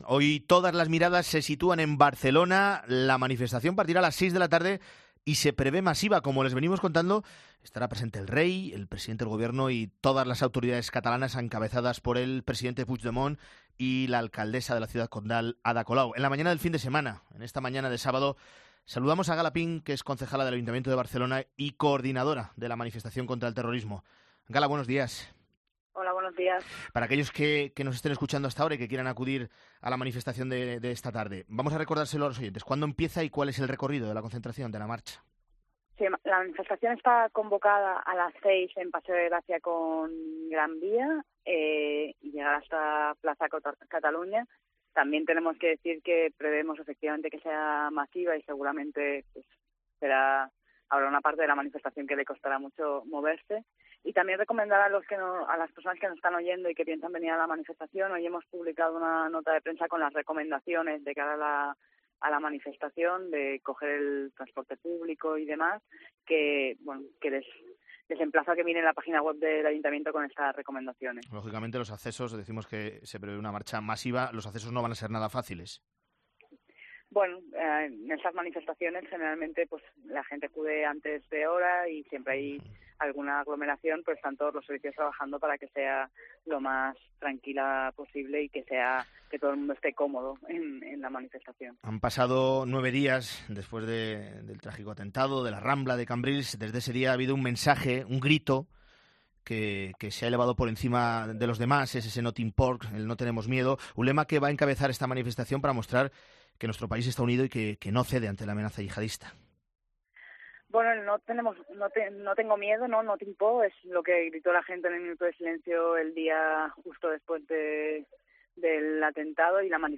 Gala Pin, concejala del Ayuntamienti de Barcelona, en 'La Mañana de Fin de Semana'